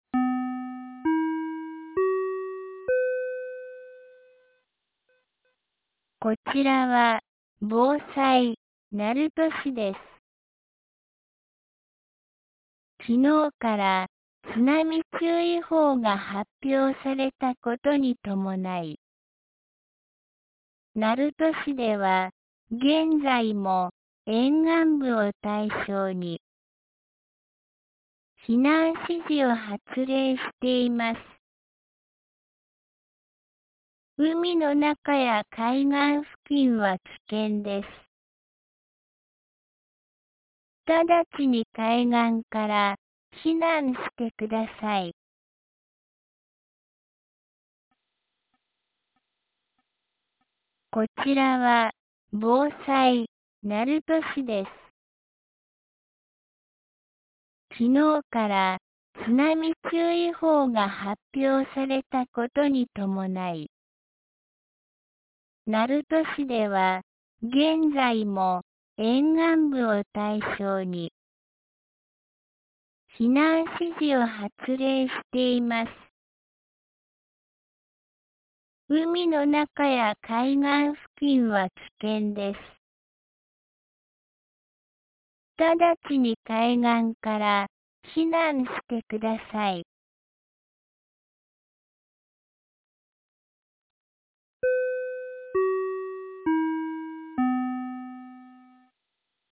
2025年07月31日 09時36分に、鳴門市より全地区へ放送がありました。